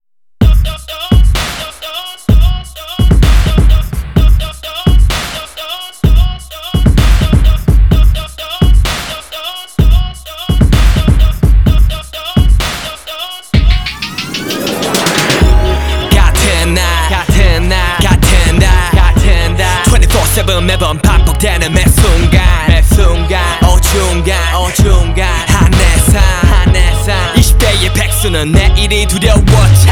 • K-Pop